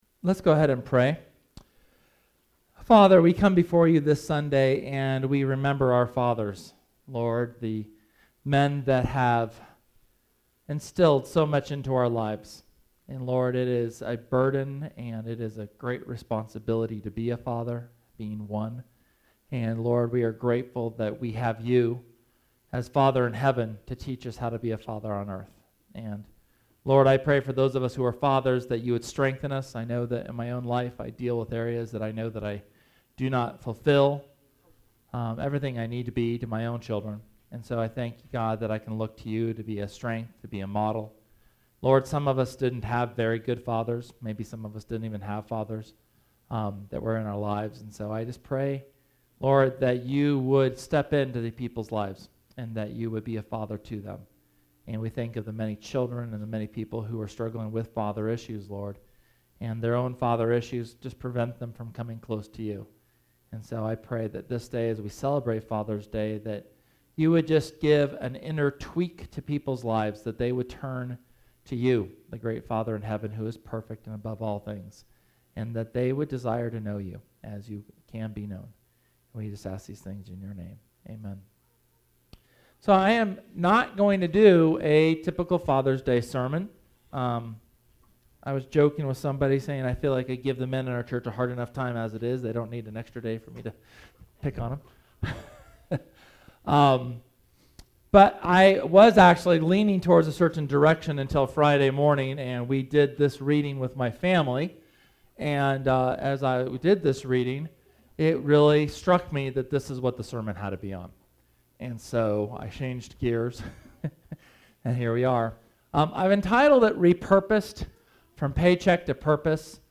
SERMON: Re-Purposed – Church of the Resurrection
Sermon from June 19th on being re-purposed by God, changing our perspective of work from paycheck to Kingdom purposes.